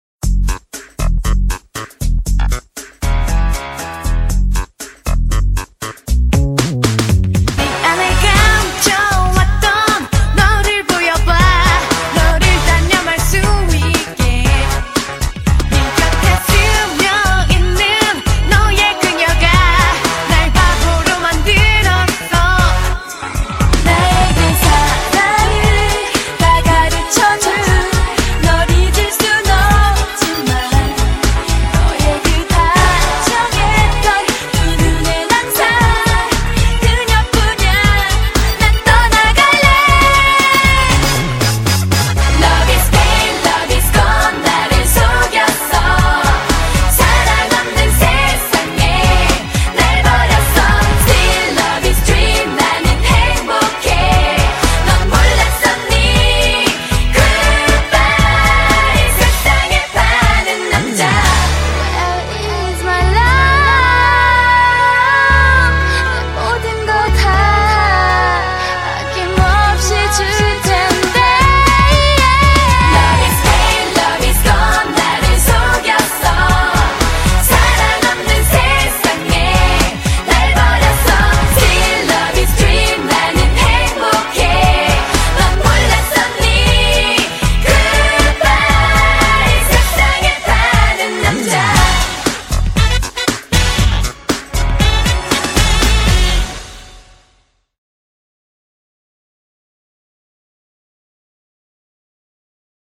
BPM118--1
Audio QualityPerfect (High Quality)